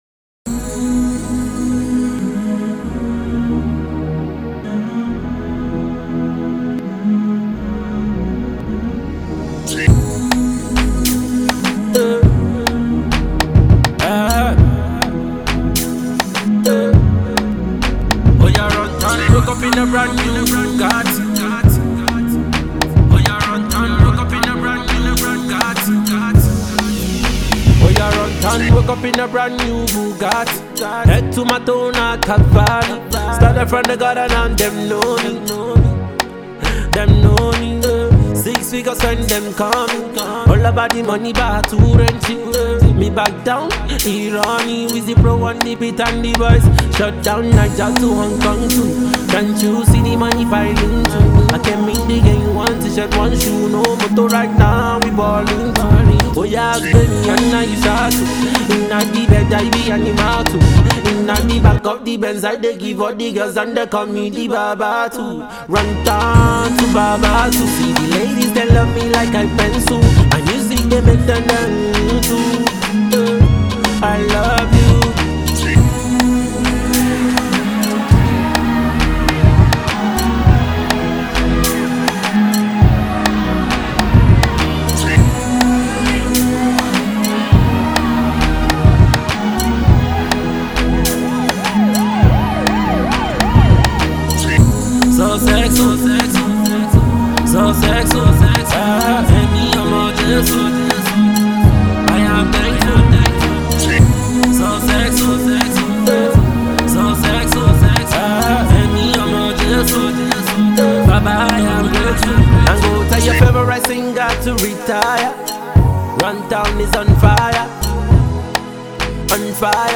as he gives us a mid-tempo Afro Pop number